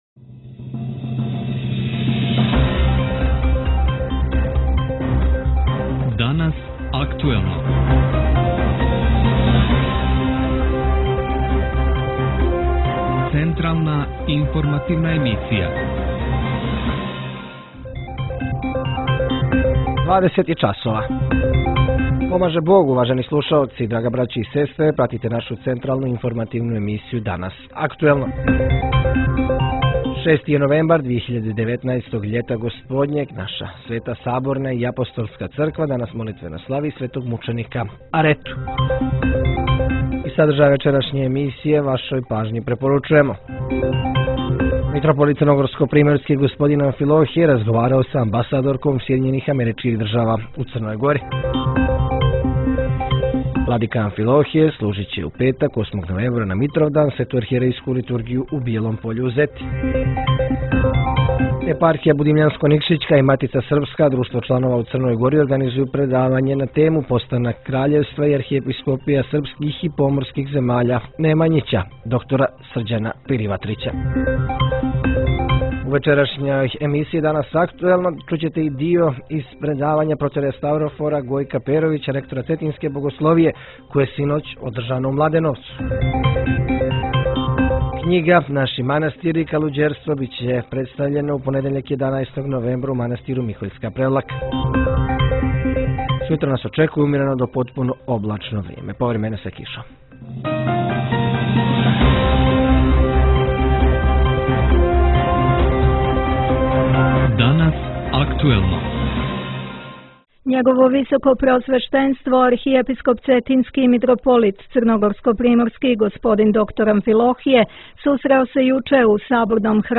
Vijesti